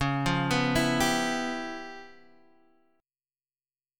Db7#9b5 Chord
Listen to Db7#9b5 strummed